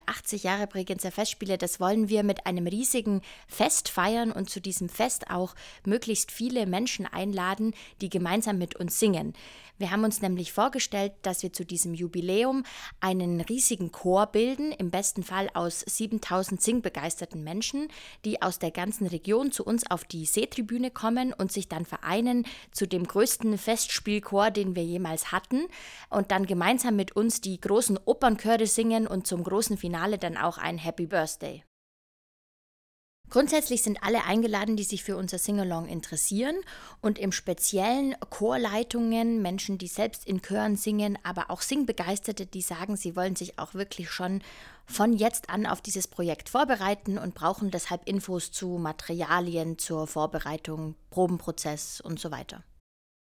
Projektpräsentation Singalong am See am 17. November 2025